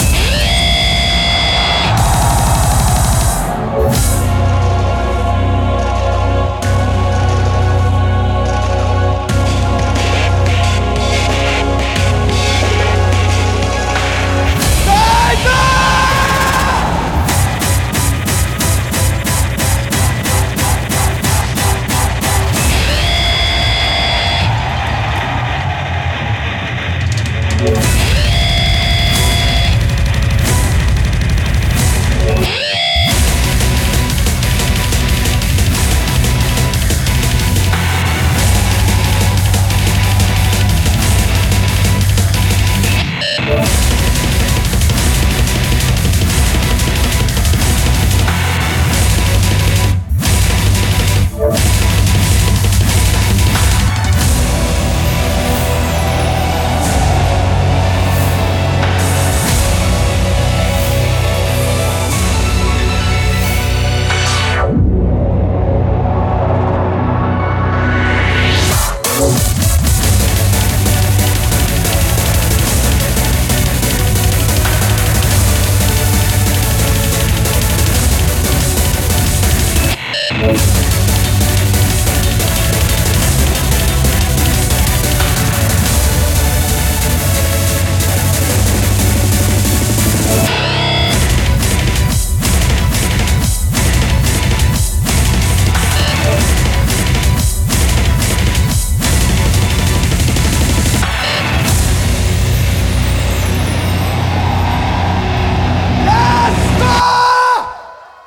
Audio QualityPerfect (Low Quality)